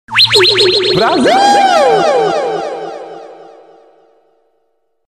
Áudio da vinheta da Globo, mas é o Gil do Vigor dizendo "Brasil"